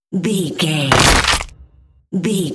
Dramatic hit bloody bone
Sound Effects
heavy
intense
dark
aggressive
hits